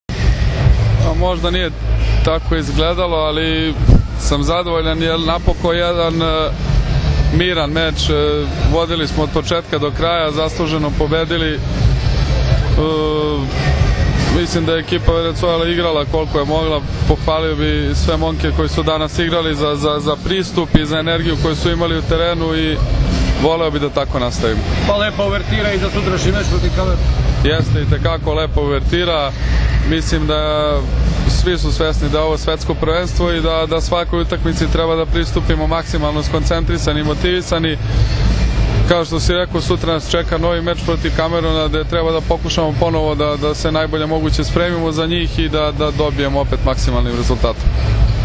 IZJAVA NIKOLE ROSIĆA